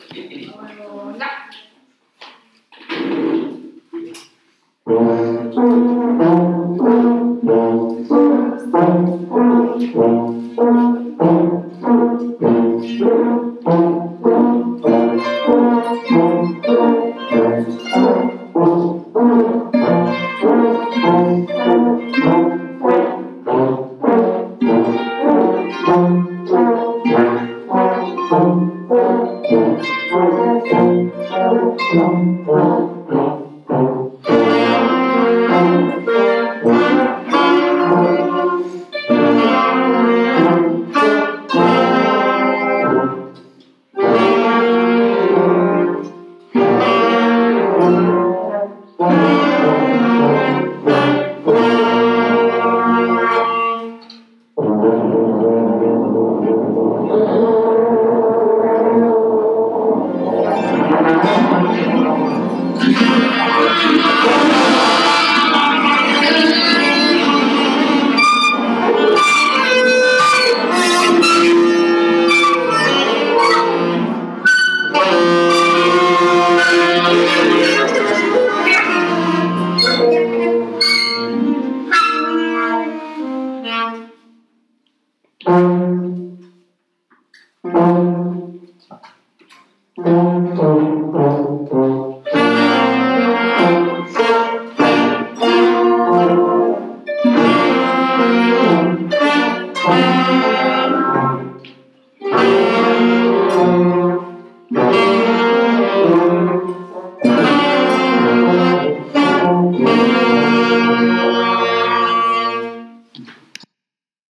Ecole Primaire Publique Annick Pizigot de Locminé Orchestre à l'école
Les élèves de CM1 et de CM2 bénéficient tous d'une pratique instrumentale sur temps scolaire.